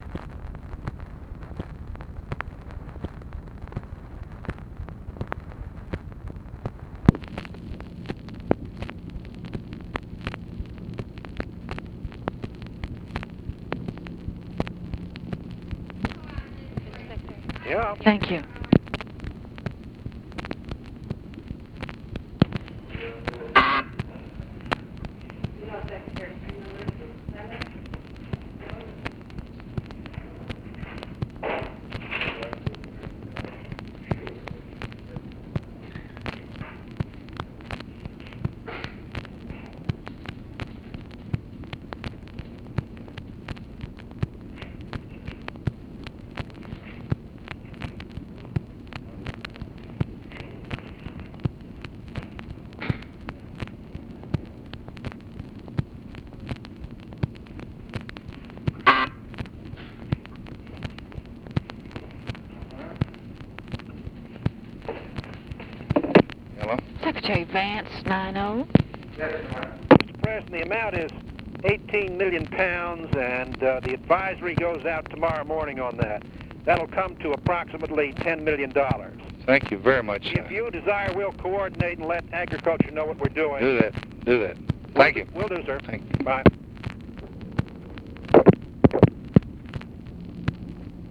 Conversation with CYRUS VANCE, March 9, 1964
Secret White House Tapes